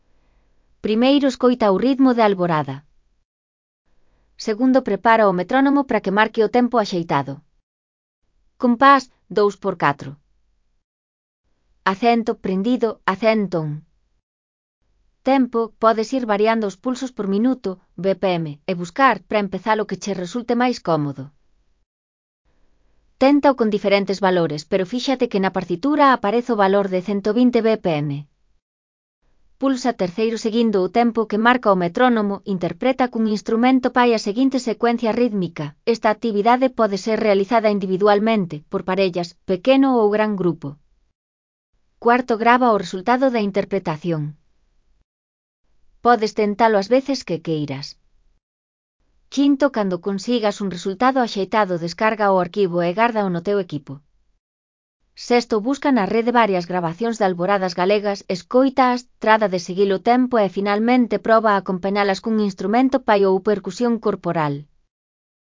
1º) Escoita o ritmo de alborada.